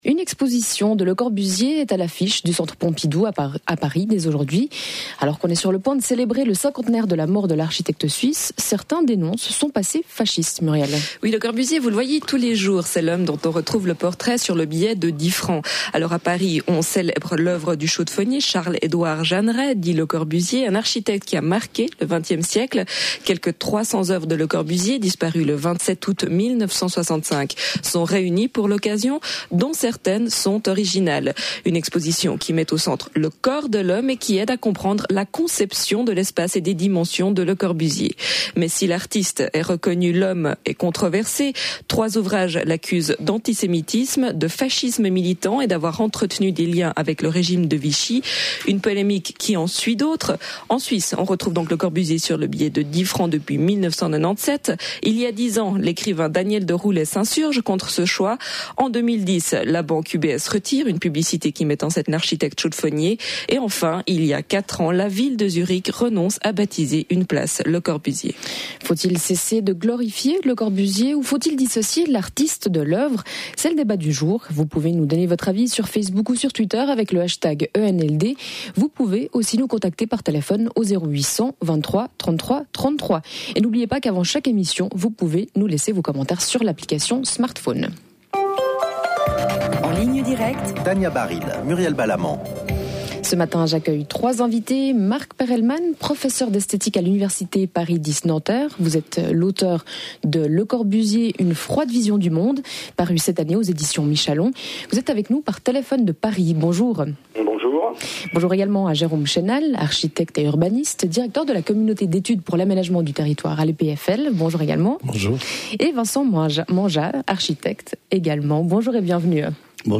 Emission En Ligne Directe de la RTS du 28 avril 2015